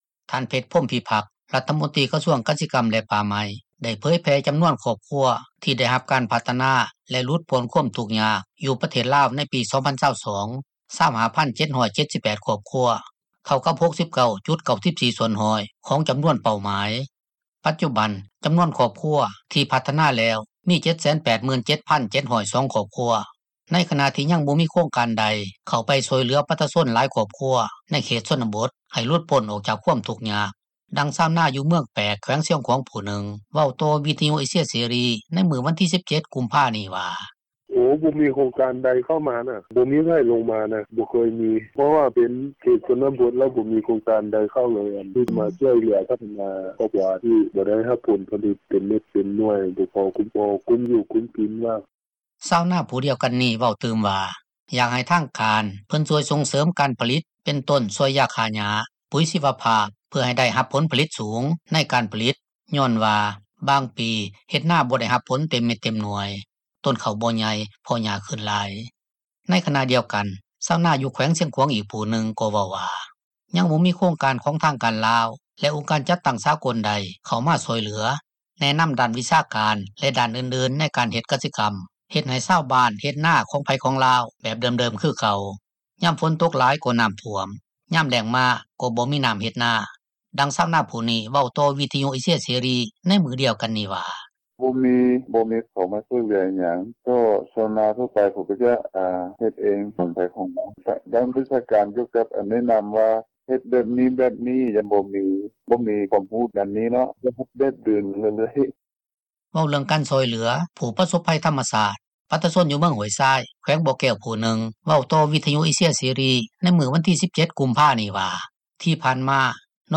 ດັ່ງຊາວນາ ຢູ່ເມືອງແປກ ແຂວງຊຽງຂວາງ ຜູ້ນຶ່ງເວົ້າຕໍ່ວິທຍຸ ເອເຊັຍ ເສຣີ ໃນມື້ວັນທີ 17 ກຸມພານີ້ວ່າ:
ດັ່ງຊາວນາຜູ້ນີ້ ເວົ້າຕໍ່ວິທຍຸ ເອເຊັຍ ເສຣີ ໃນມື້ດຽວກັນນີ້ວ່າ: